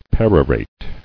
[per·o·rate]